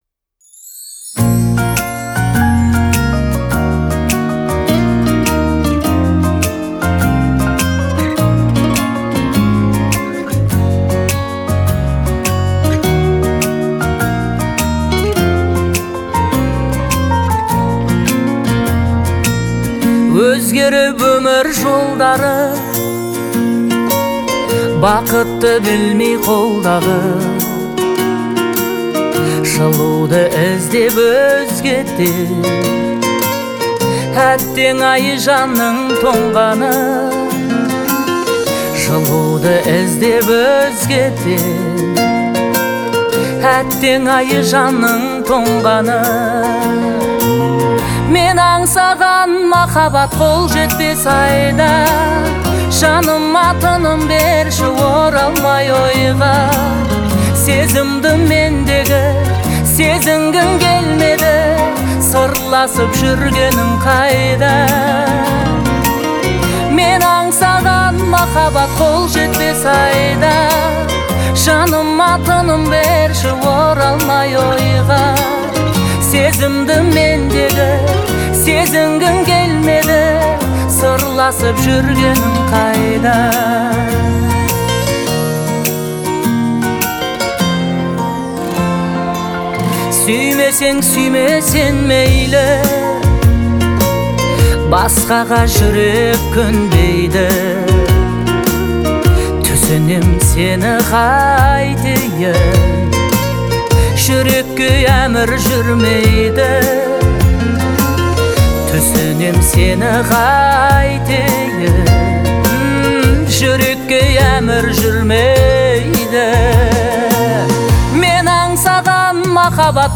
это трогательная казахская песня в жанре поп